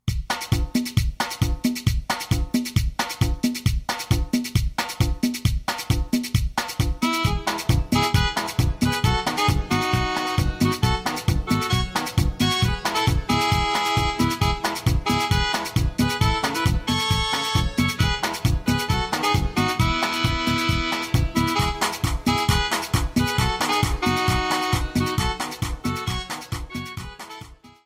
70-Technobanda.mp3